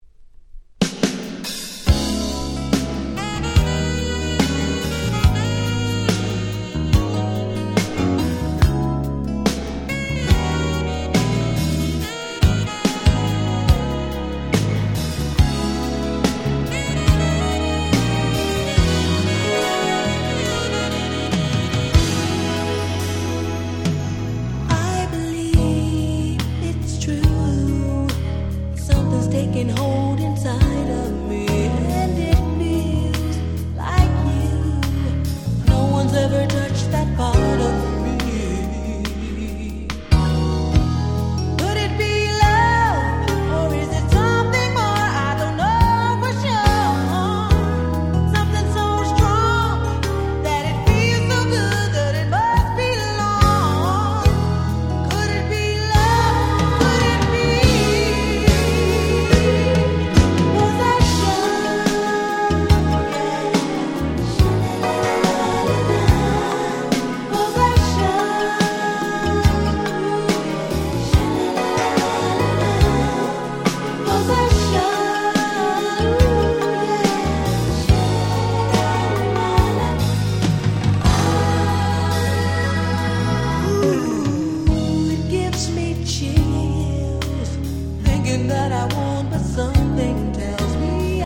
もう超〜良いMidナンバー！！
80's R&B